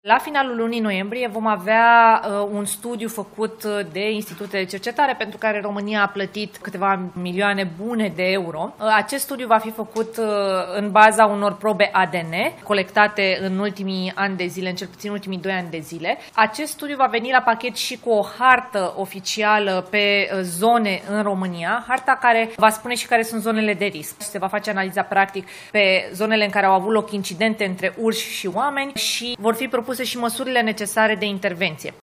Ministra Mediului, Diana Buzoianu a precizat, la finalul ședinței de guvern, că în afara localităților, se menține aplicarea graduală a măsurilor de intervenție în cazul urșilor.
Ministra Mediului, Diana Buzoianu: „Acest studiu va veni la pachet și cu o hartă oficială pe zone în România, harta care va spune și care sunt zonele de risc”